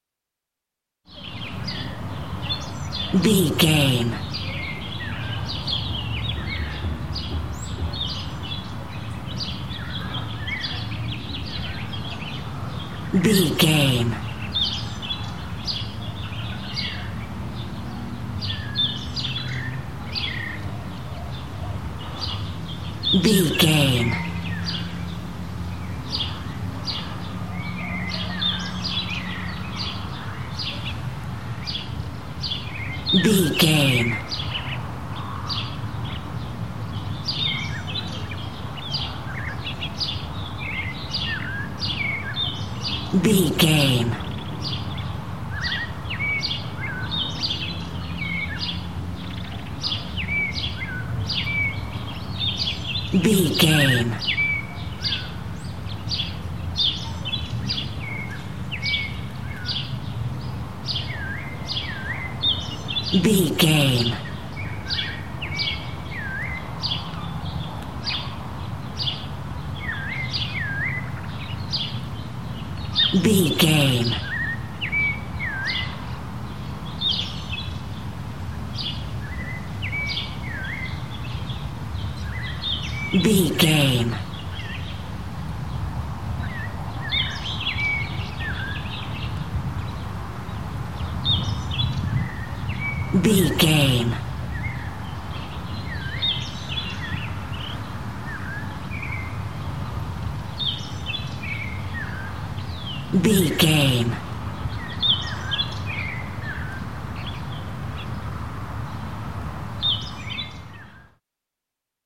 Country day birds wind
Sound Effects
calm
nature
peaceful
ambience